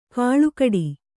♪ kāḷukaḍi